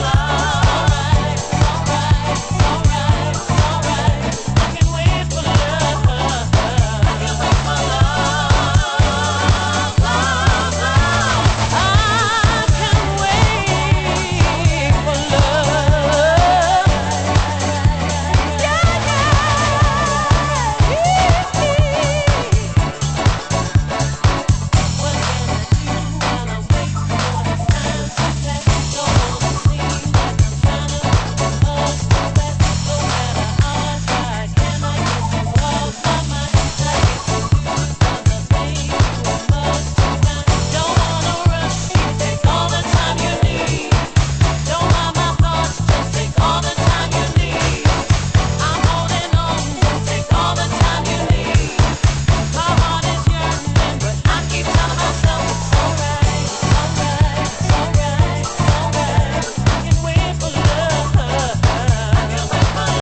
★DEEP HOUSE 歌